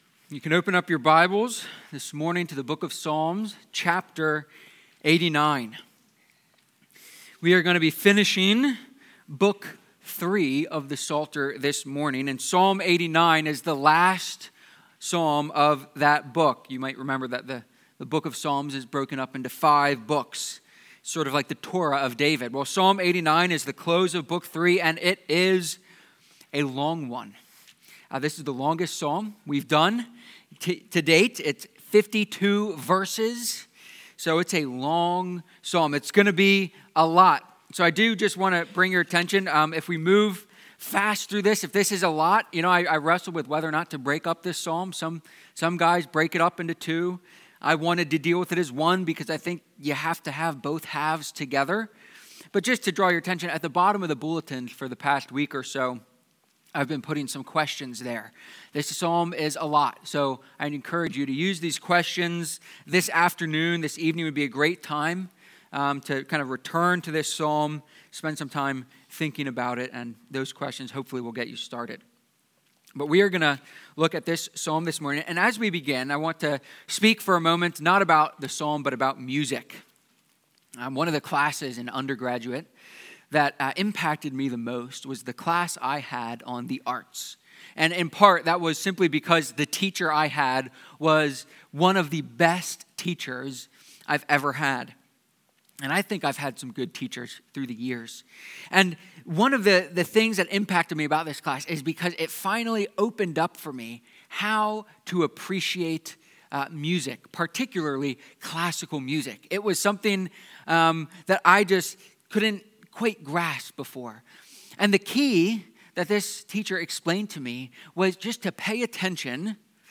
Psalm-89-sermon.mp3